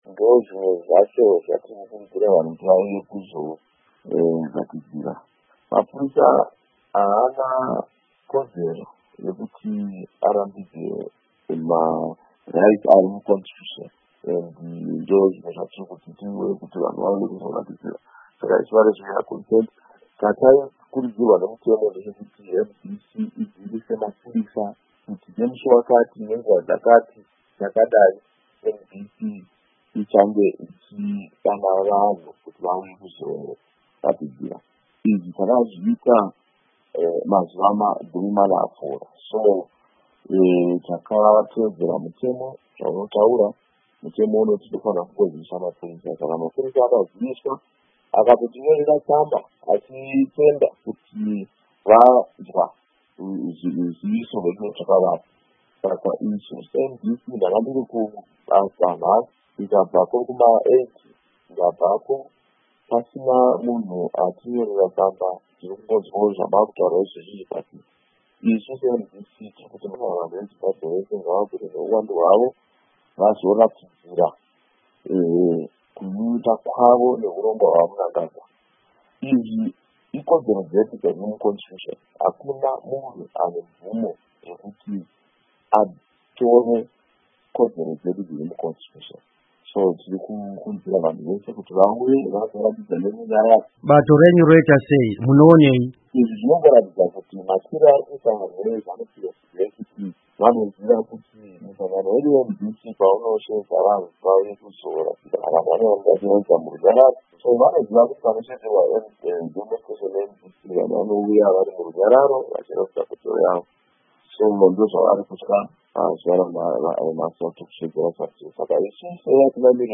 Hurukuro naVaCharlton Hwende